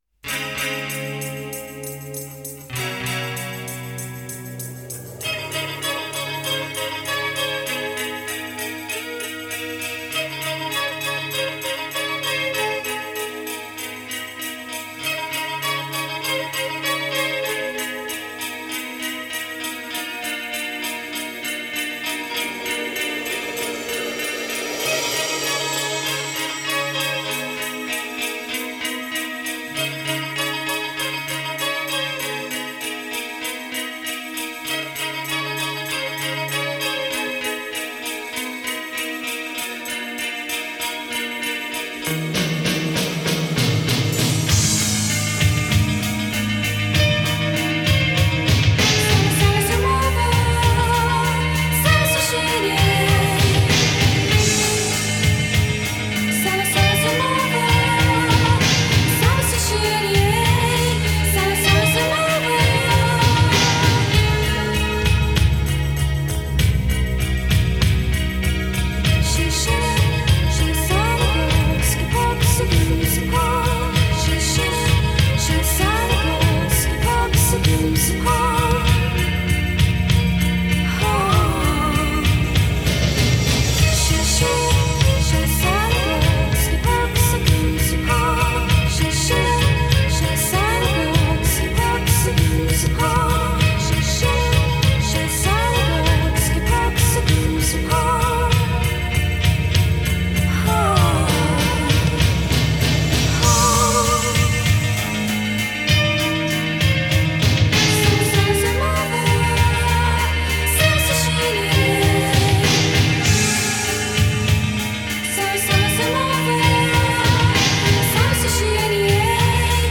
Ethereal